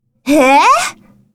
萝莉音唉一声音效_人物音效音效配乐_免费素材下载_提案神器
萝莉音唉一声音效免费音频素材下载